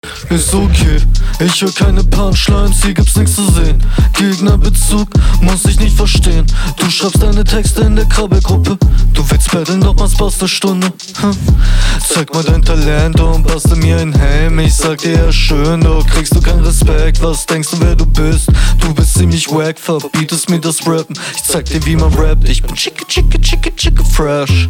Dich versteht man wenigstens.
Flowlich auch solide.